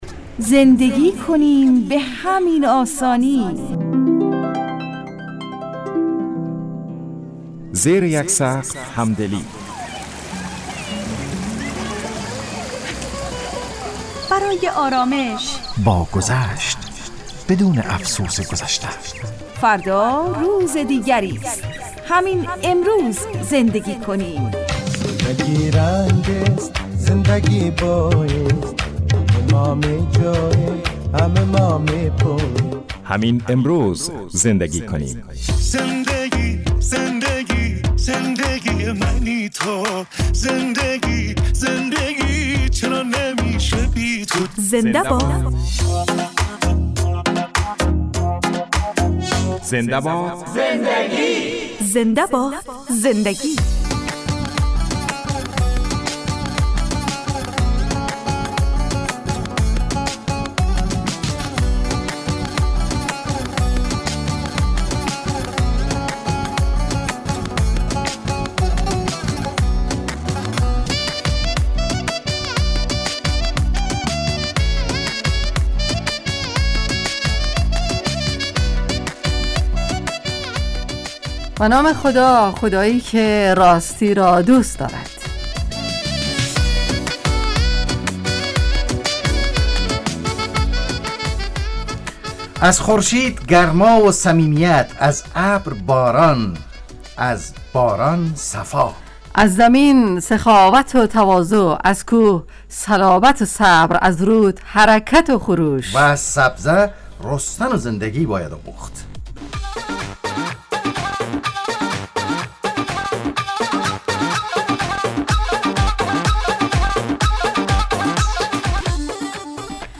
زنده باد زندگی __ برنامه خانوادگی رادیو دری__ زمان پخش ساعت :10:05 تا 11 صبح به وقت افغانستان __موضوع : راست و دروع _ تهیه کننده